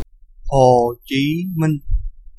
Pronunciation of Ho Chi Minh